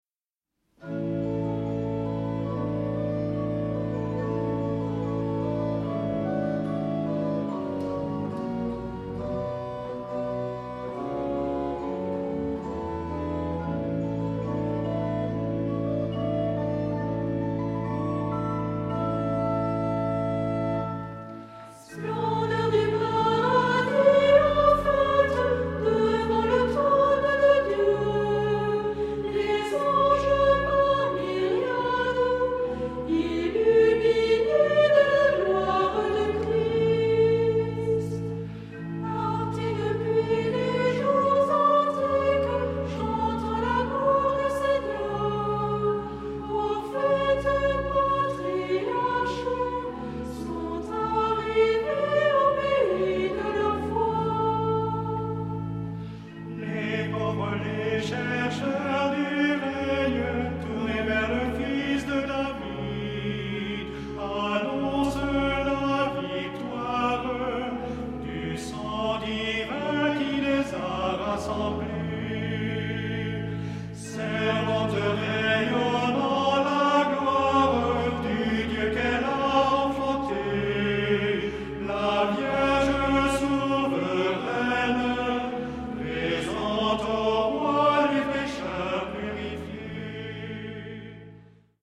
Genre-Style-Forme : Hymne (sacré)
Caractère de la pièce : vivant ; léger
Type de choeur :  (1 voix unisson )
Instrumentation : Orgue  (1 partie(s) instrumentale(s))
Tonalité : la majeur